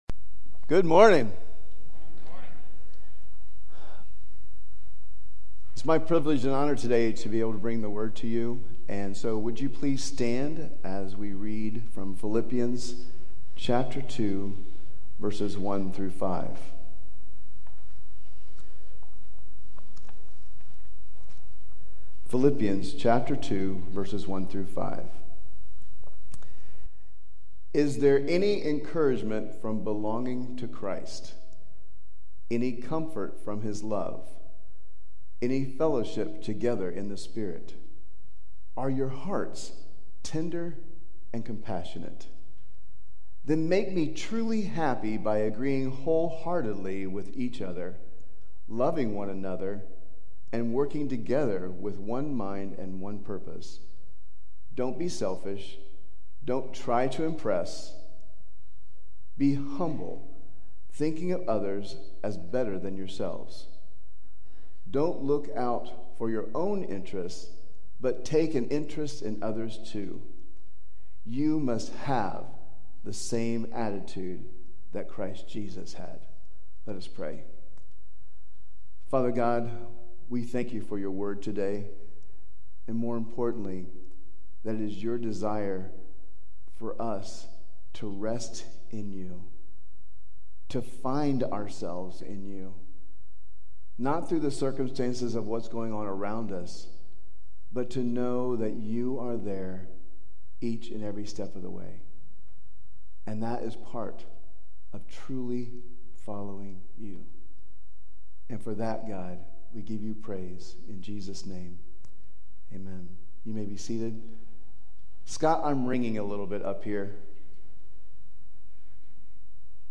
We encourage you to review the notes during the sermon or through the week!